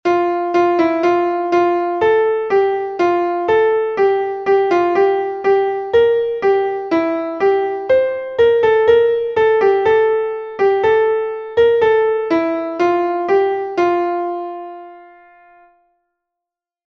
Entoación a capella